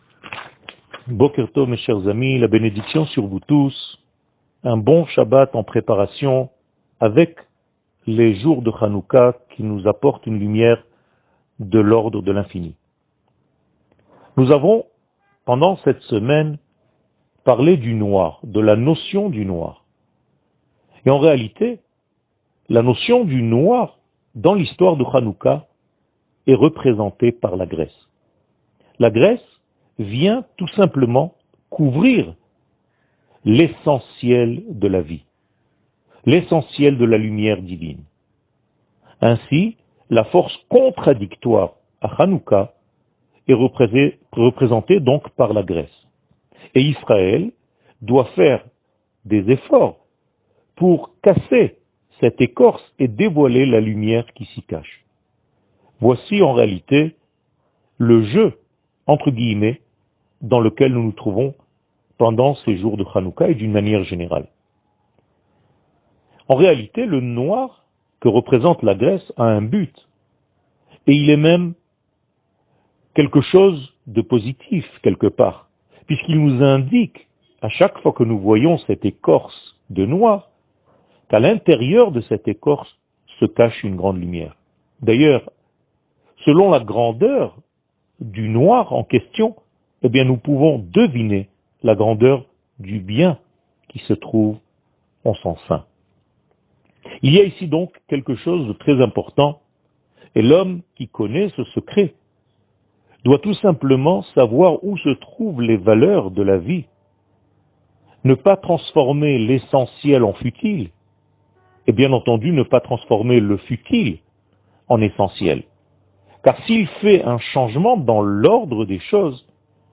שיעור מ 07 דצמבר 2021